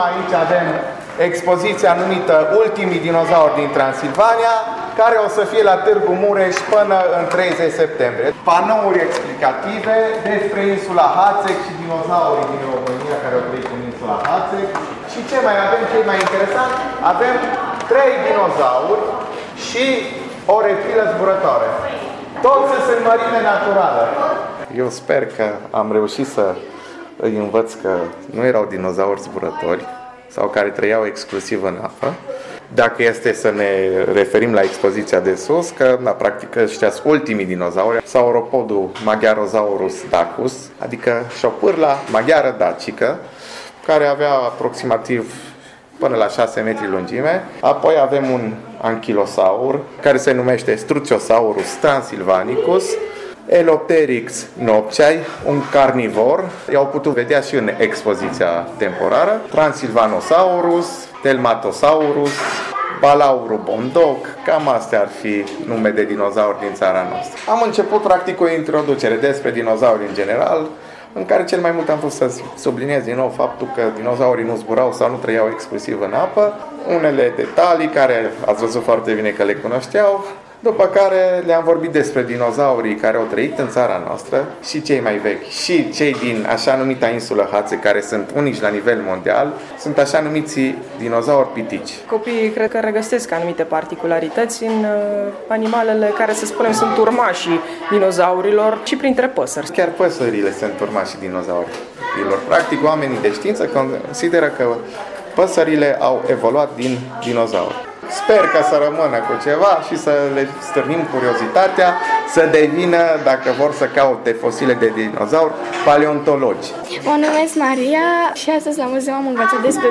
Peste 25 de copii au participat la atelierul despre dinozaurii din România, la Muzeul de Științe ale naturii, în cadrul programului de vară „Vacanța la muzeu”.
31-iulie-Copilarii-Dinozauri-la-muzeu.mp3